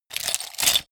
Gemafreie Sounds: Büroarbeit